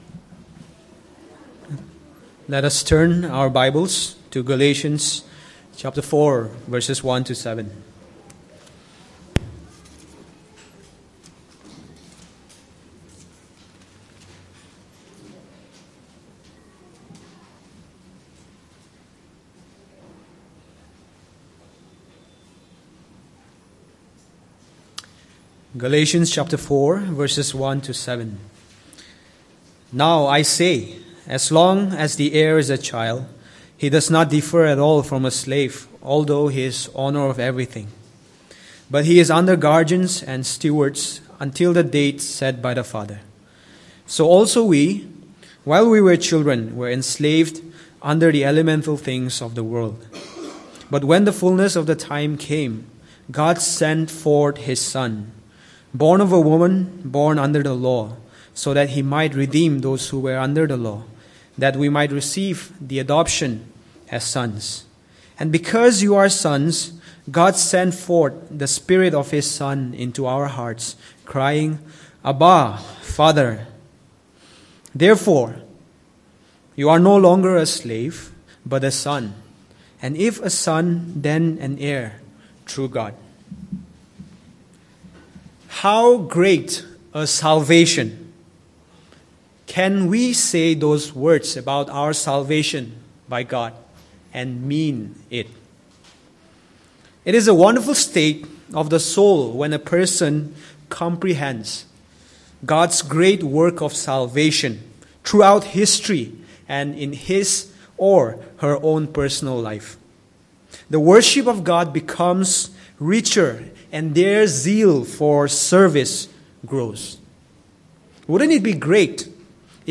Service Type: Sunday Morning
9th-Nov-2025-Sermon.mp3